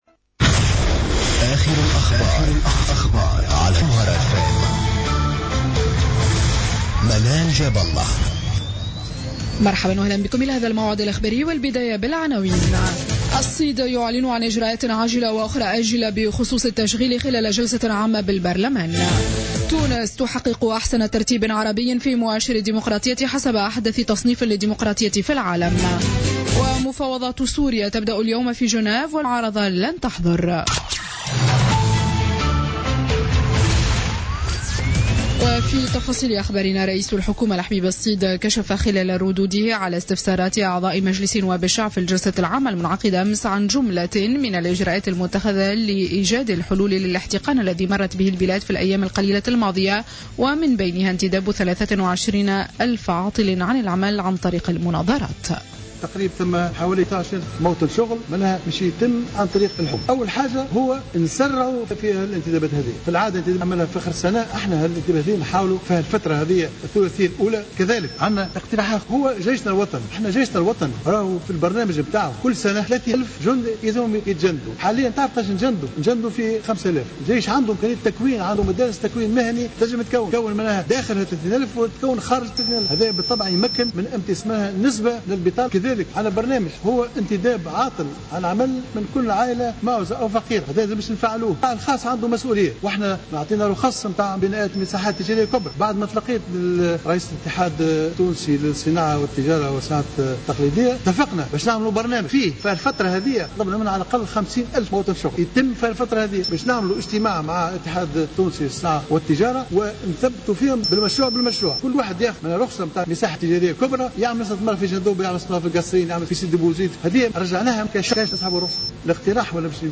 Journal Info 00h00 du vendredi 29 janvier 2016